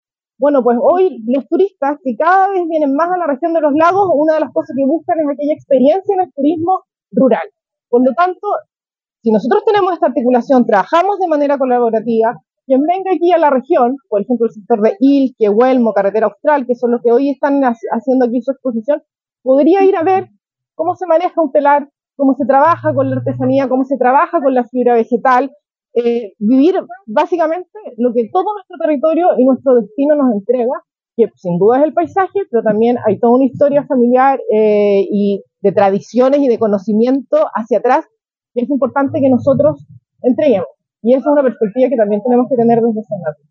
La actividad se realizó junto a productoras y cultoras de la región que dieron vida a una nueva versión del tradicional “Mercado Campesino” de Indap, en instalaciones del Parque Costanera de Puerto Montt; en el marco de la Semana de las Mipymes y Cooperativas 2024 que desarrolló la Seremía de Economía y sus servicios dependientes.
En esa misma línea, la directora regional del Sernatur, Claudia Renedo, aseguró que este trabajo conjunto busca relevar todo el potencial del turismo rural y el mundo campesino del territorio como un producto turístico que cada vez es más preferido por quienes nos visitan